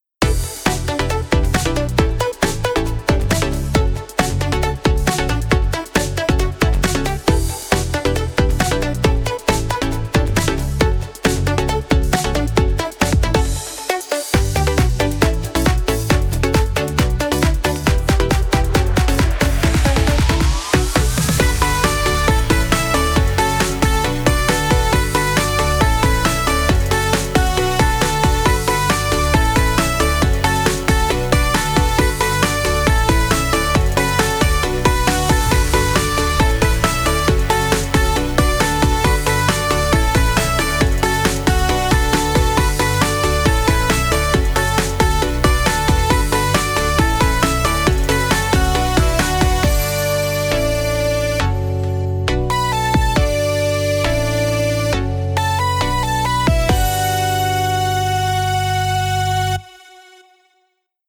明るい雰囲気のかわいくてコミカルなBGMです。